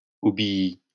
[obiji](info) ìsch a frànzeescha Gmainda mìt 53 Iiwoohner (Schtànd: 1.